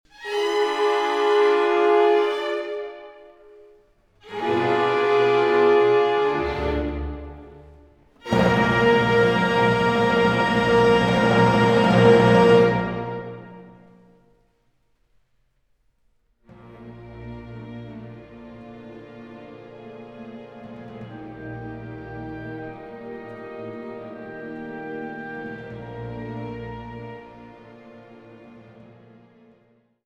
Oboe
Trompete